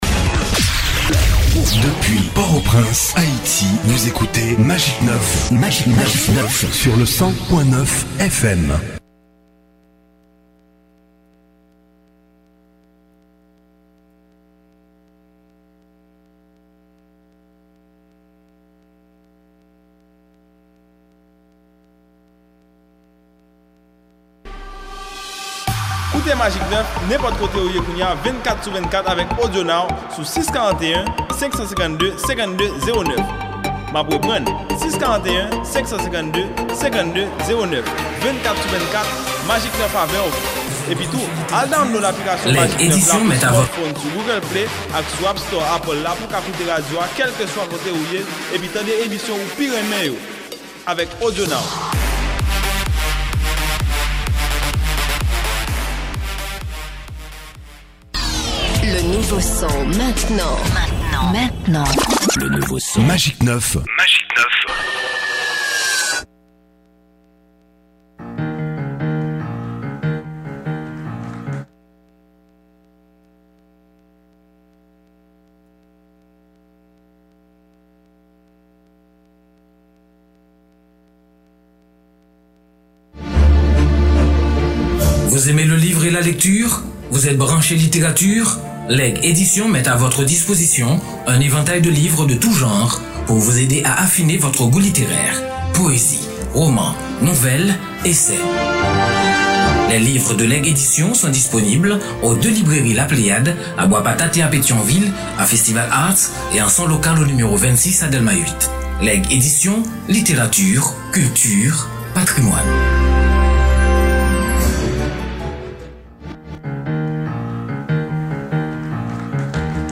Invités: Les présentateurs lisent des extraits de textes autour du séisme